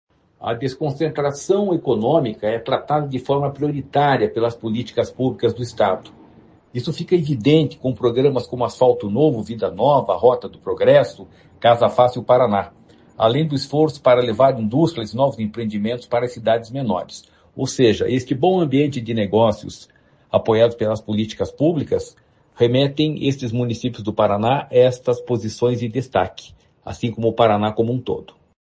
Sonora do diretor-presidente do Ipardes, Jorge Callado, sobre o Paraná ter 8 cidades entre as 100 maiores economias do país